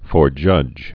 (fôr-jŭj)